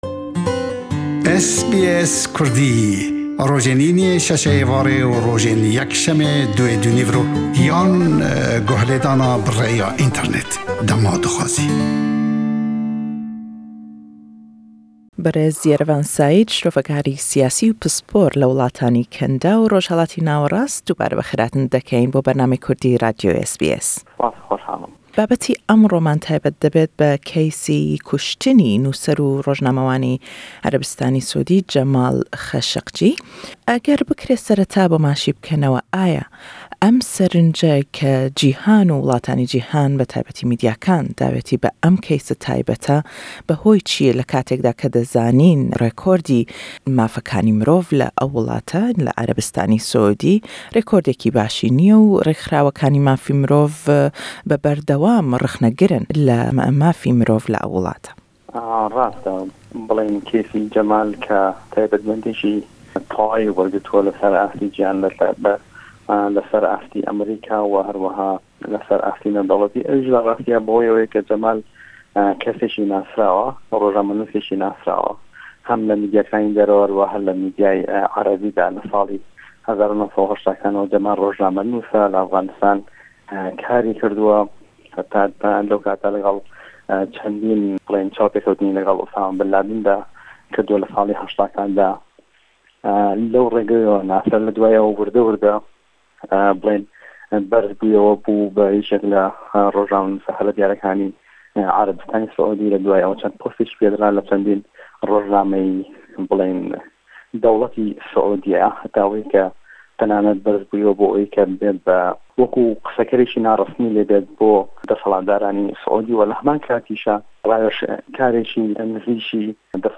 Le em lêdwane da le gell şirovekarî siyasî û pisporr le willatanî Kendaw û Rojhellatî Nawerast